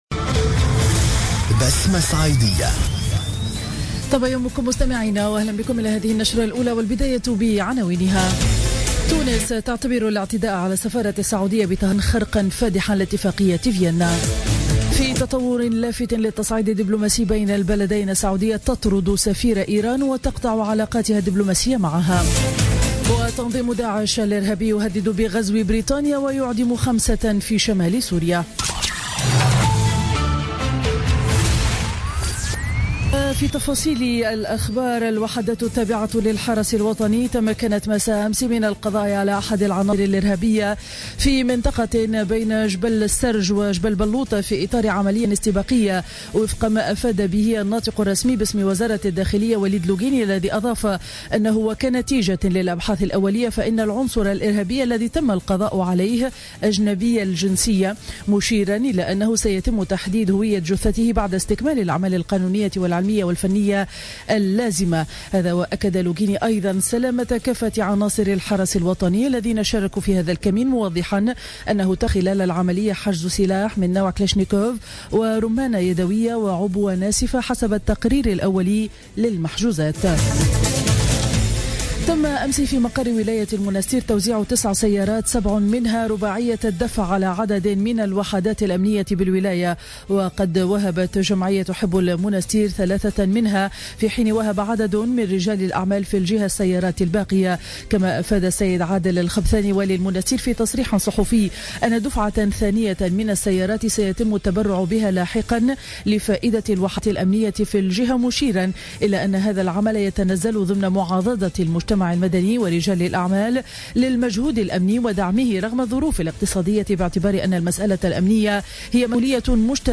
نشرة أخبار السابعة صباحا ليوم الاثنين 04 جانفي 2016